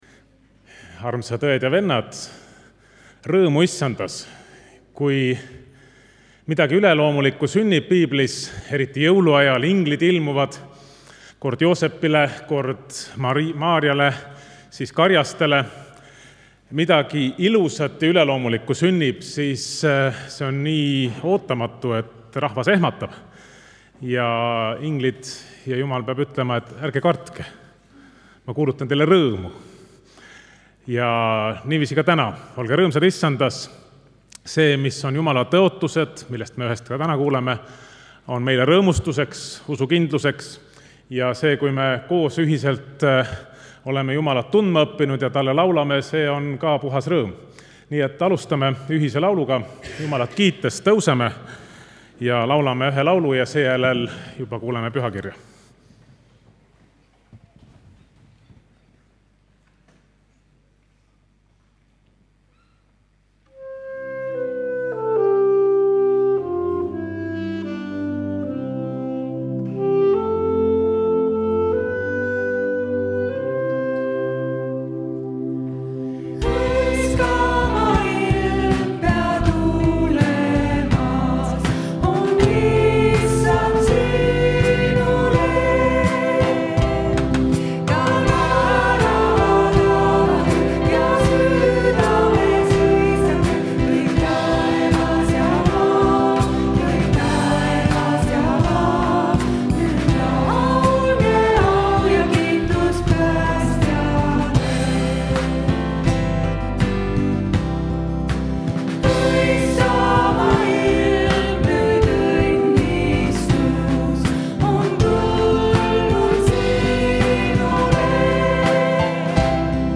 Pühakirja lugemine ja palve
Jutlus